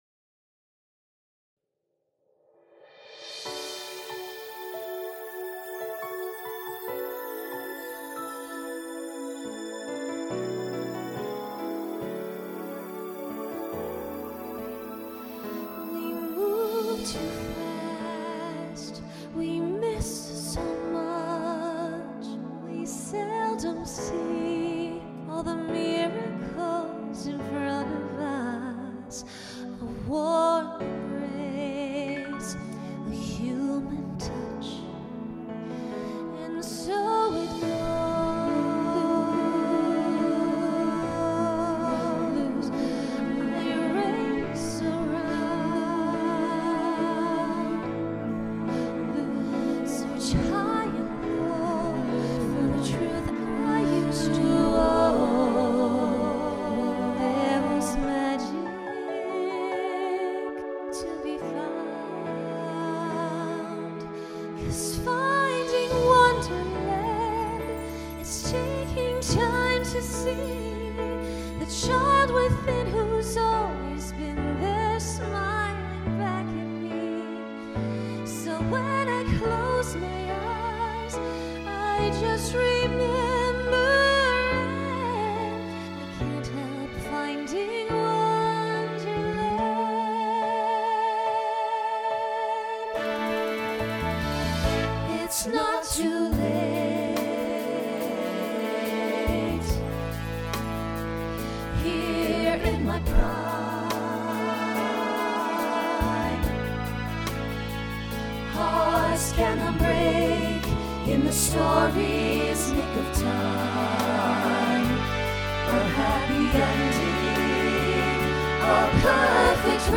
Voicing SATB Instrumental combo Genre Broadway/Film
Show Function Ballad